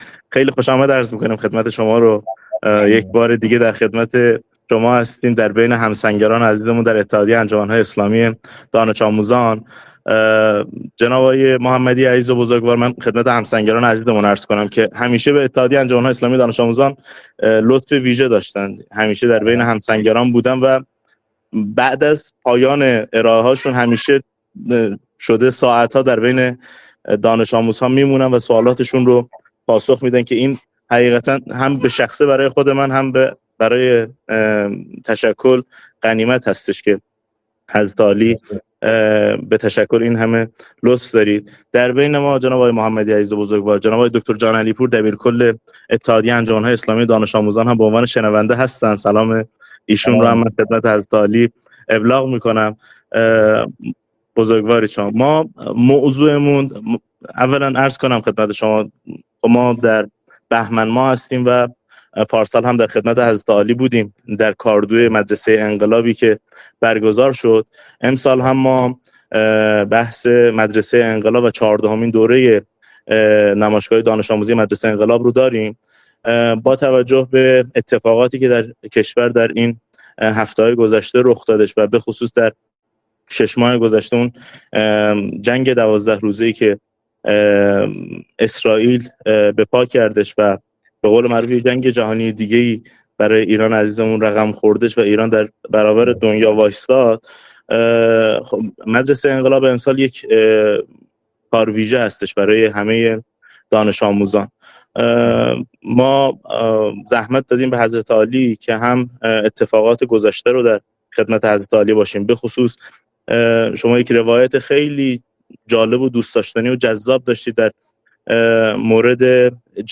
نشست تبیینی